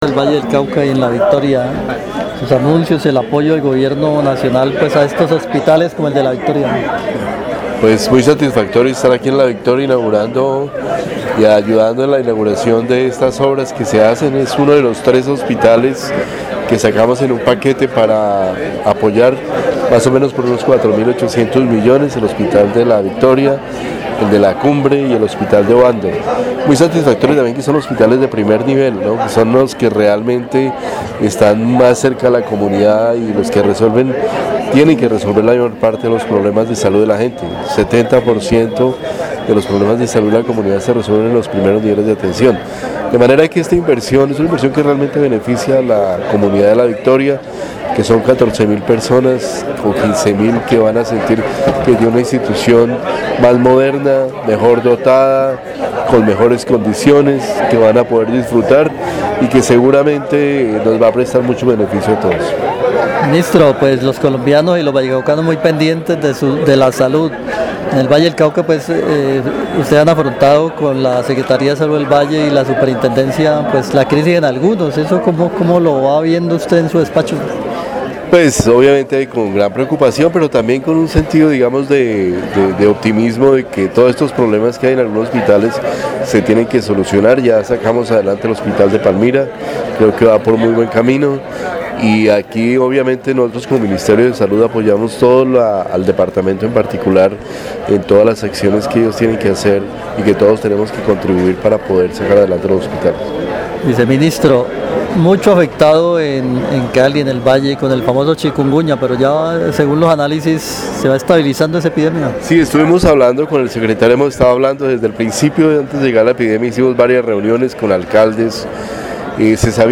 Audio: Declaraciones de ViceSalud sobre infraestructura hospitalaria
-    El Viceministro Fernando Ruíz asistió al lanzamiento de las unidades de obstetricia y urgencias de la ESE Hospital Nuestra Señora de Los Santos en La Victoria, Valle.